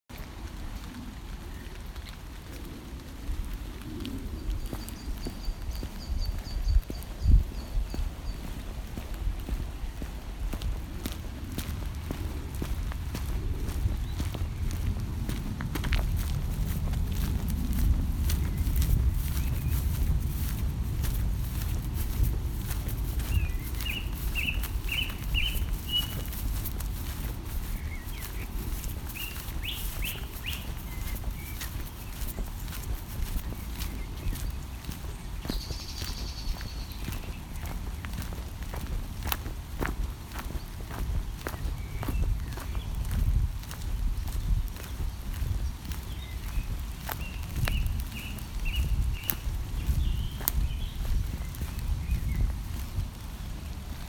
caught in the rain